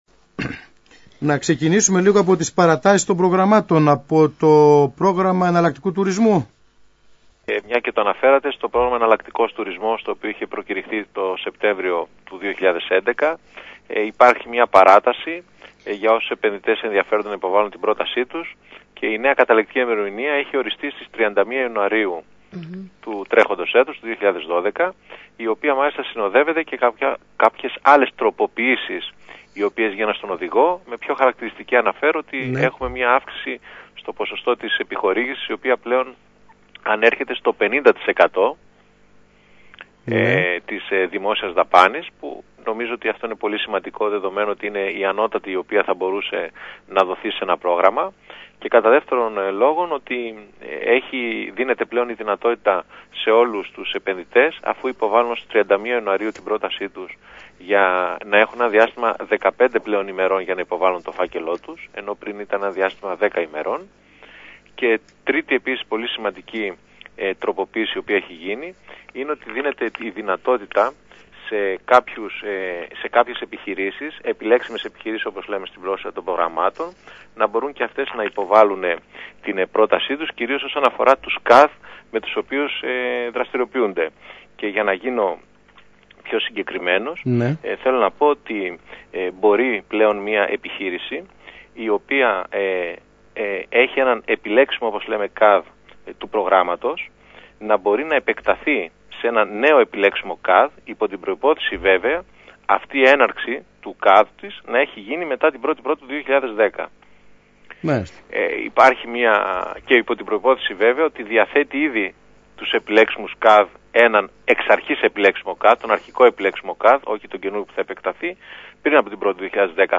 Ραδιοφωνική Συνέντευξη